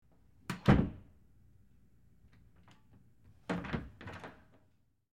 Basic Wood Door Open And Close, X2